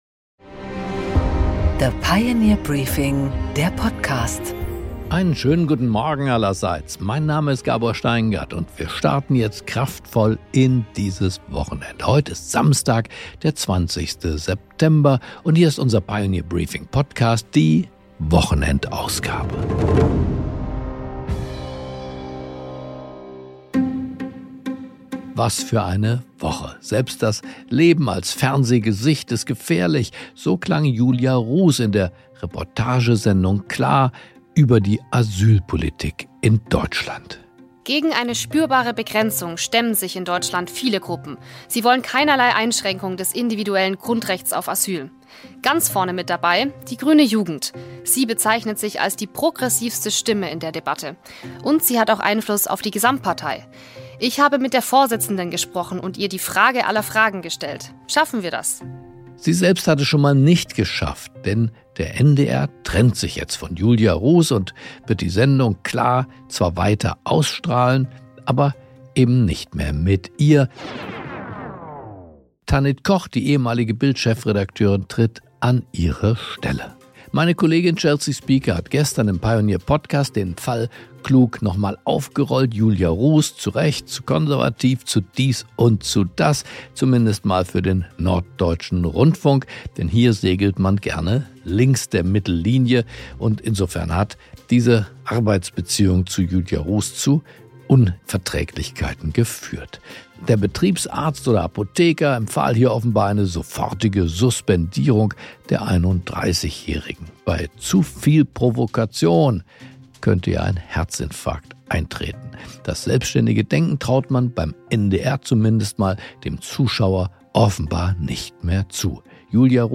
Gabor Steingart präsentiert die Pioneer Briefing Weekend Edition
Eine pointierte Einordnung der Woche von Gabor Steingart in Fakten und Stimmen.